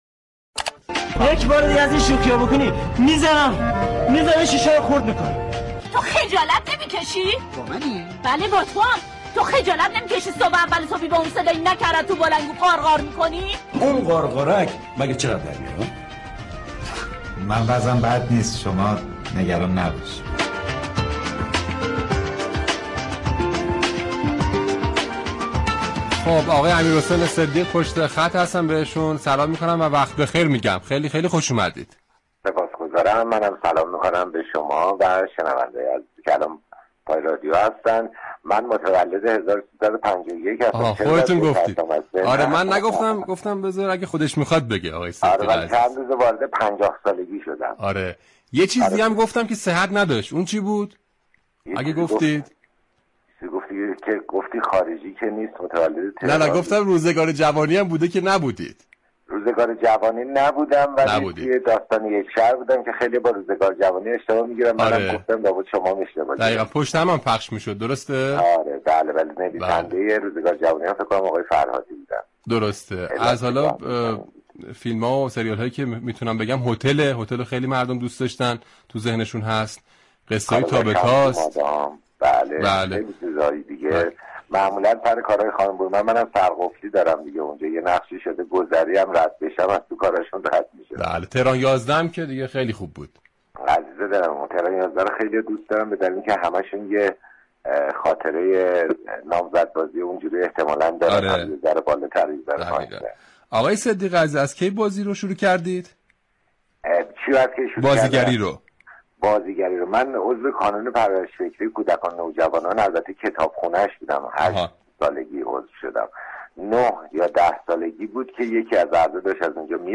امیر حسین صدیق در گفتگو با «عصر صبا»: كتاب هنوز بین مردم ارزشمند است